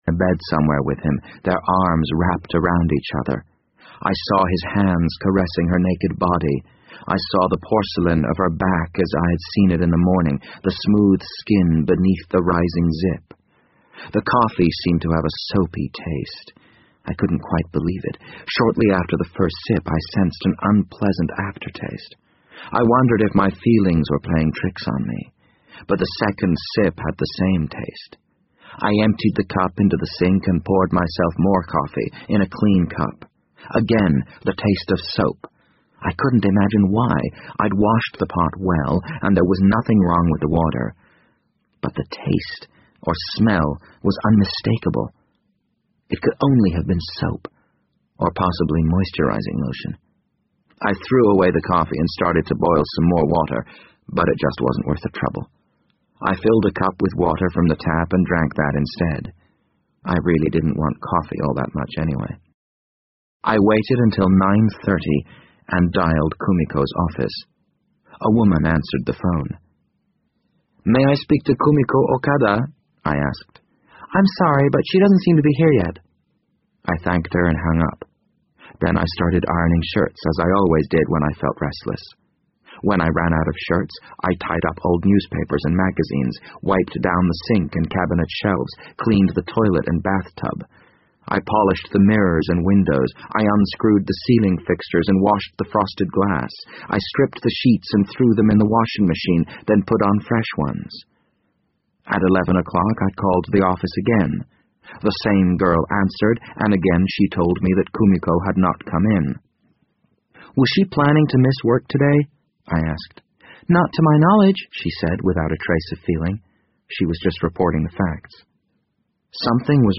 BBC英文广播剧在线听 The Wind Up Bird 005 - 7 听力文件下载—在线英语听力室